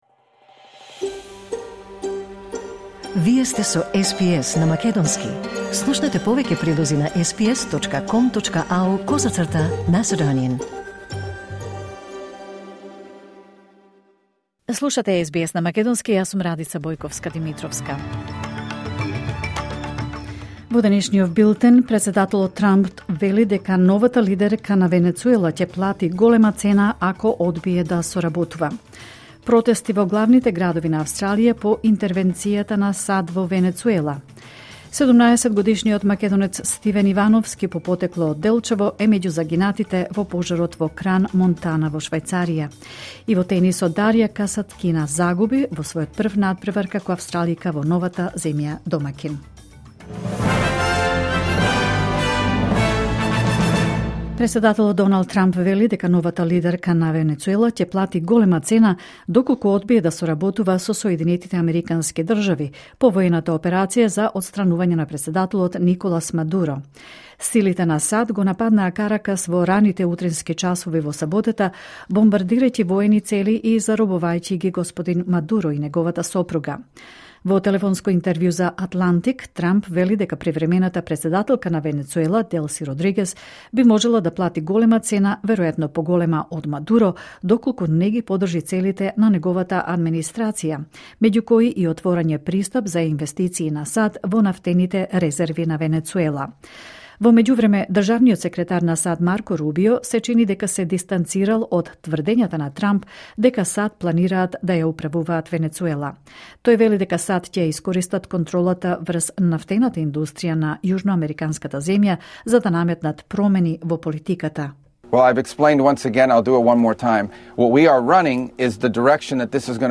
Вести на СБС на македонски 5 јануари 2026